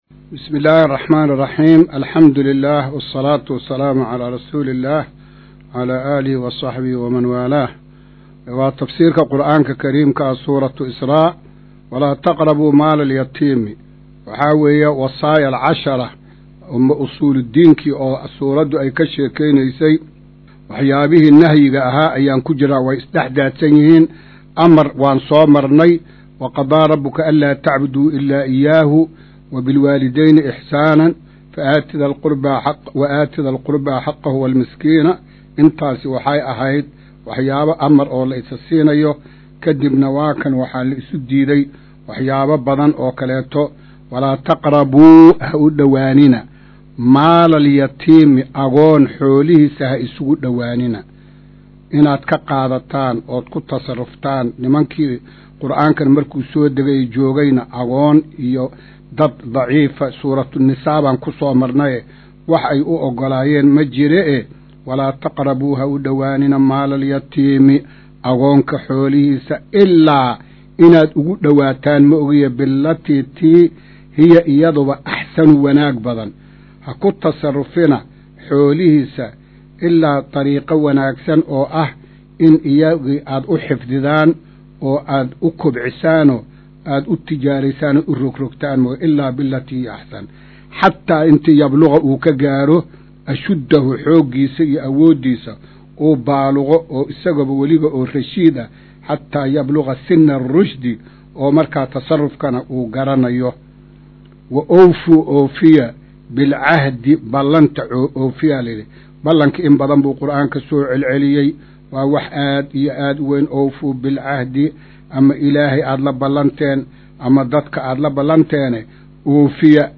Maqal:- Casharka Tafsiirka Qur’aanka Idaacadda Himilo “Darsiga 139aad”